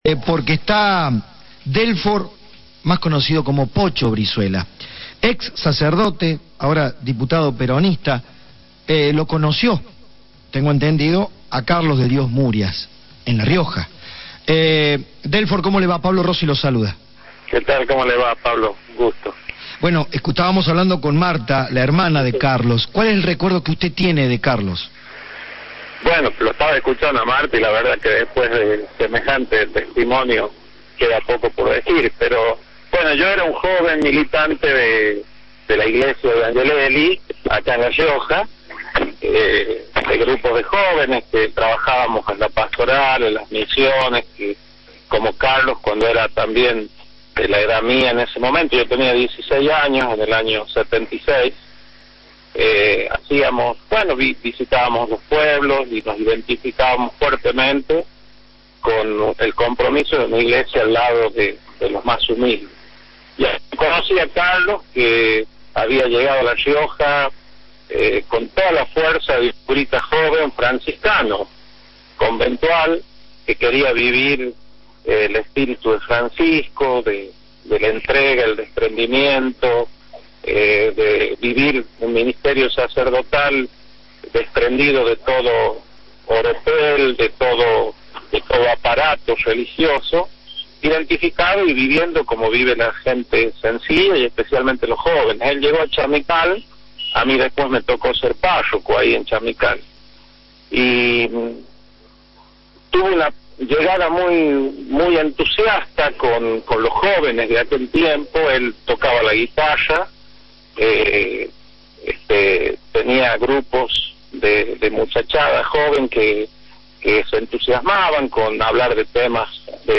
Delfor Brizuela, ex sacerdote y actual diputado, por Cadena 3